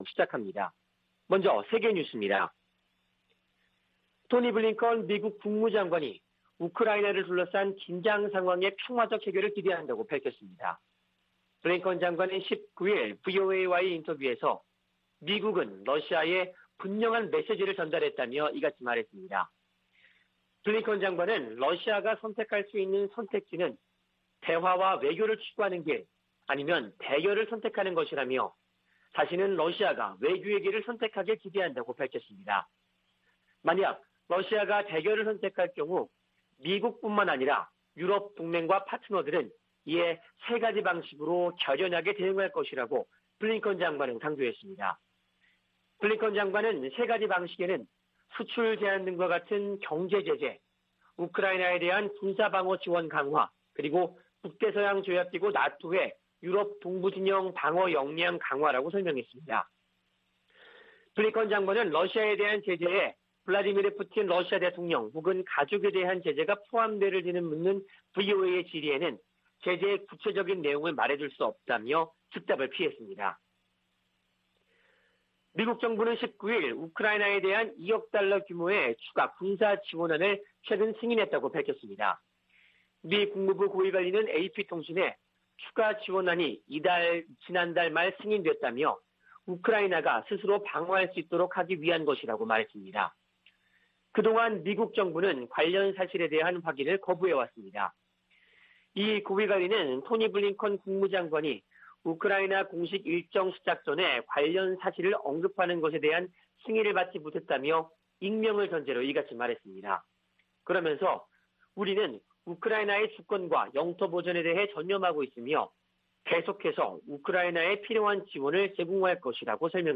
VOA 한국어 '출발 뉴스 쇼', 2021년 1월 20일 방송입니다. 미국의 린다 토머스-그린필드 유엔 대사는 북한의 미사일 발사를 '공격' 행위로 규정하고 유엔 차원 대응을 예고했습니다. 미국 등 6개국이 북한의 탄도미사일 발사에 대응한 안보리 긴급 회의 소집을 요청했습니다. 미국은 북한의 미사일 발사가 제기하는 위협을 평가절하하지 않으며, 관련 기술 진전을 심각하게 보고 있다고 국방부 대변인이 밝혔습니다.